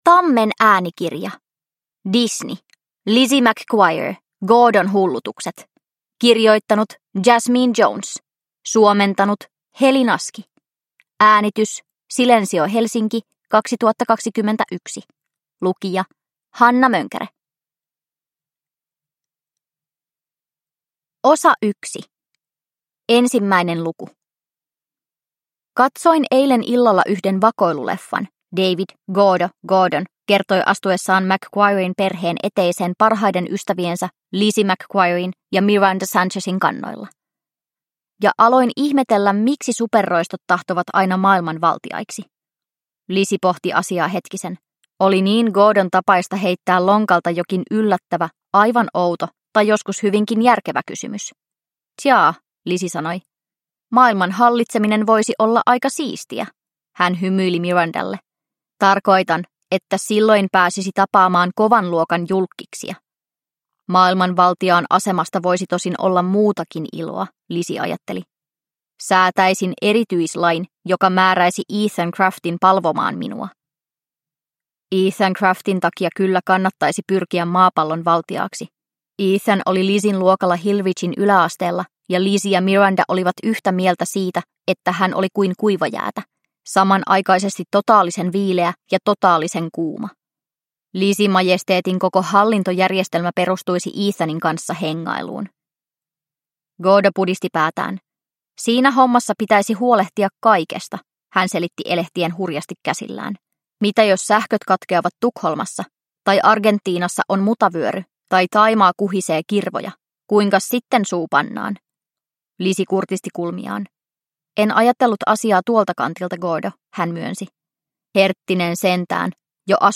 Lizzie McGuire. Gordon hullutukset – Ljudbok – Laddas ner